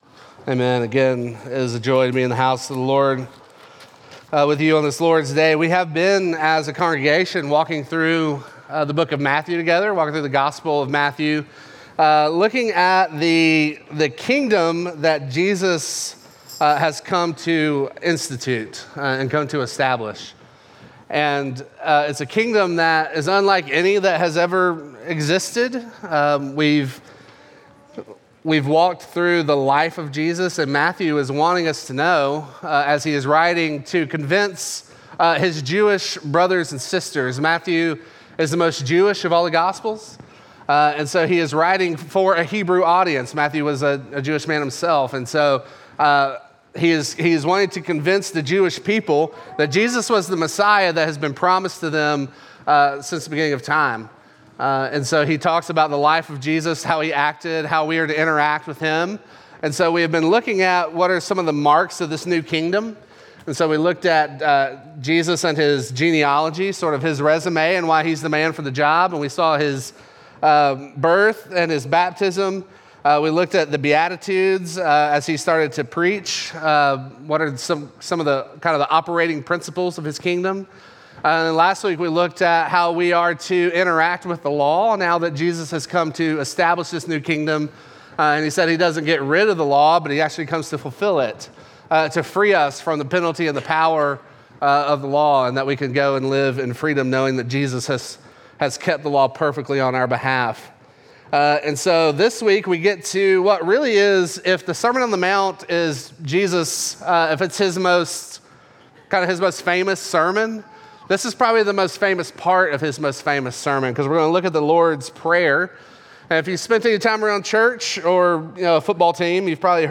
Midtown Fellowship 12 South Sermons Conversing With The King Mar 17 2024 | 00:35:57 1x 00:00 / 00:35:57 Subscribe Share Apple Podcasts Spotify Overcast RSS Feed Share Link Embed